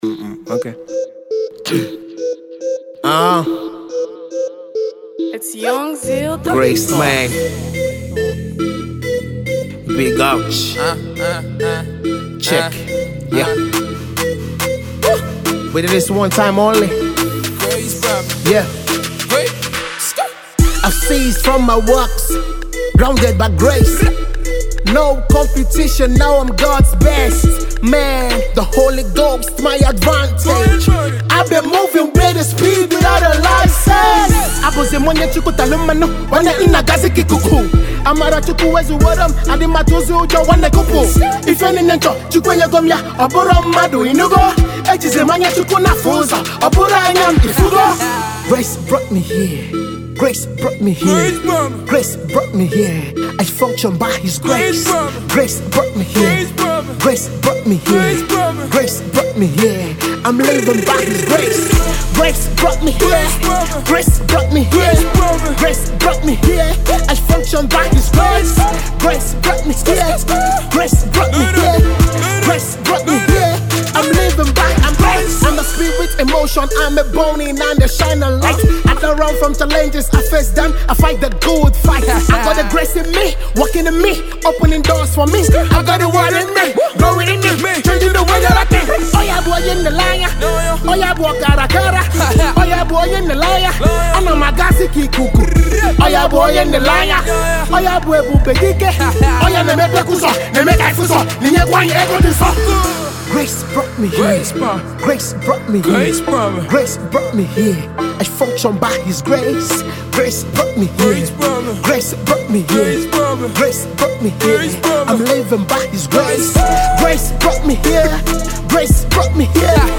Gospel Rapper releases new rap single